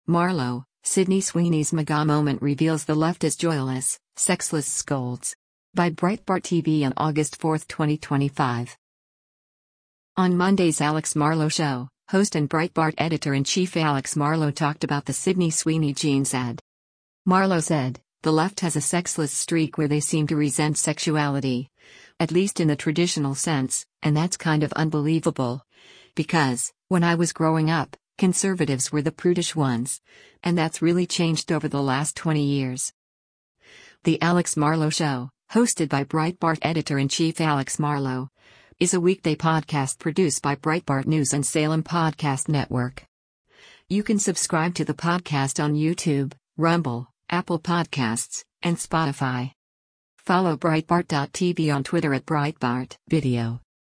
On Monday’s “Alex Marlow Show,” host and Breitbart Editor-in-Chief Alex Marlow talked about the Sydney Sweeney jeans ad.